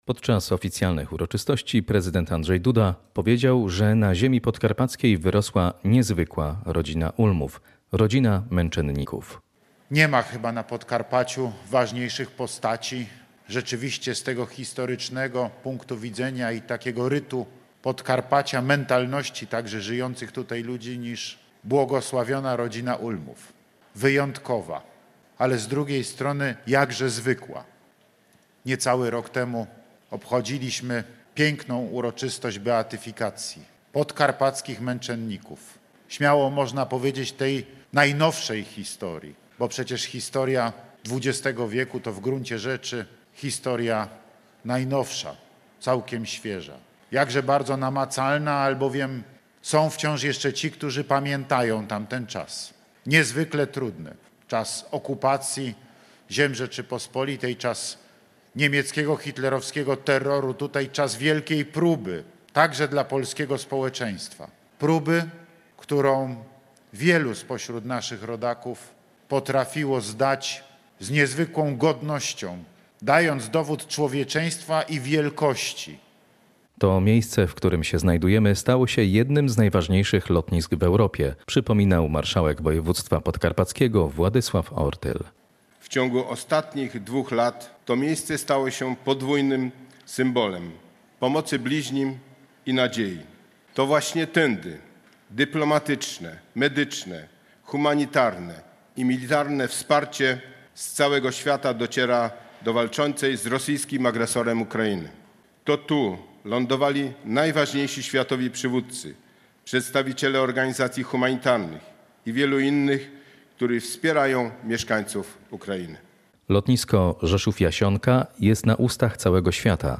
Z historycznego punktu widzenia oraz rytu mentalności ludzi żyjących na Podkarpaciu nie ma ważniejszych postaci niż błogosławiona Rodzina Ulmów – mówił w niedzielę prezydent Andrzej Duda podczas uroczystości nadania imienia Rodziny Ulmów portowi lotniczemu Rzeszów–Jasionka.
Relacja